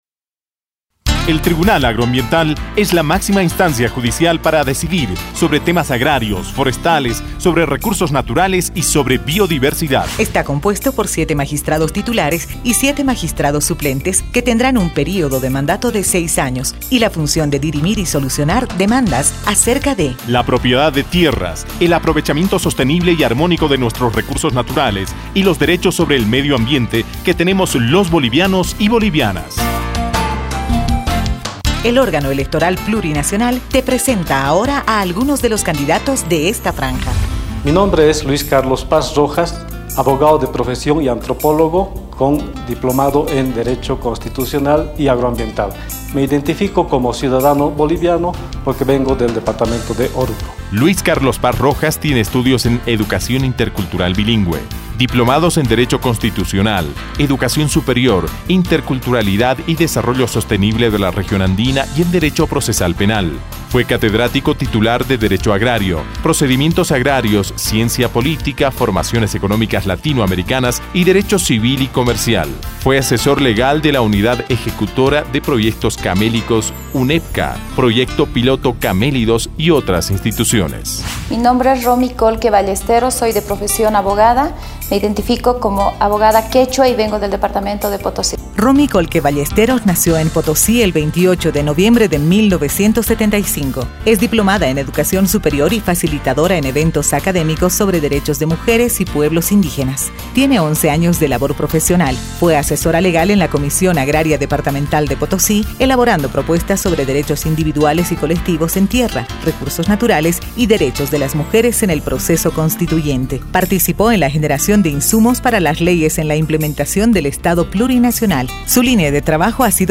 Cuñas